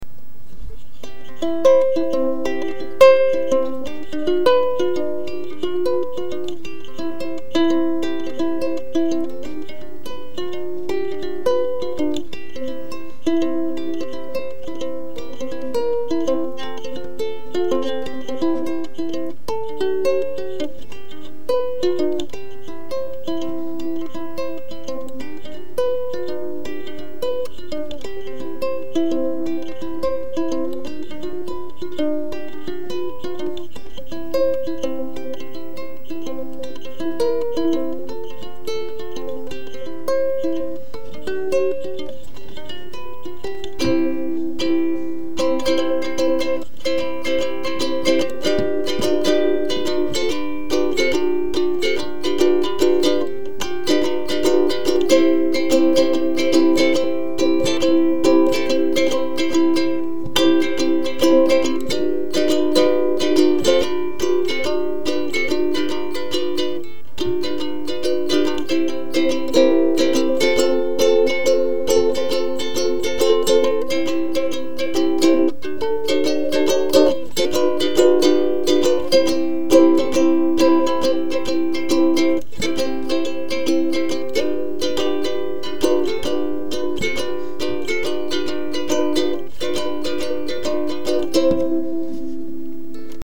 revenons au chose un peu plus acceptable. mon premier ukulélé.
tout érable avec table en épicéa. toujours pas mal de défaut de finition et surtout une table un poil trop épaisse qui bride bien le son. par contre, j’étais assez content de la rosace en forme de soleil couchant.